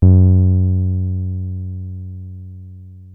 303 F#2 6.wav